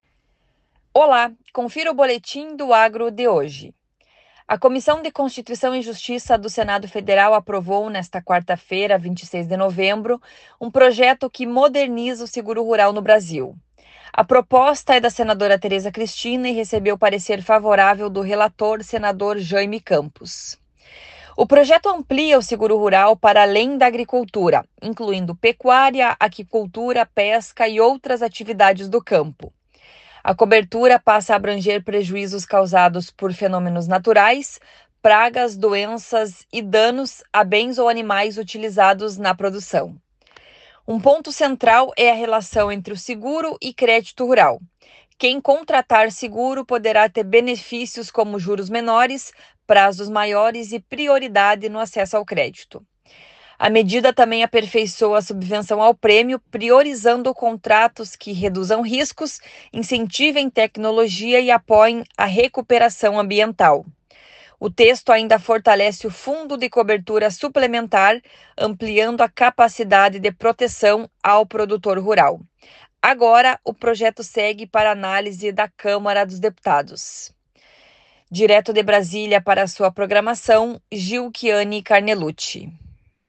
Senado aprova novo Seguro Rural. Direto de Brasília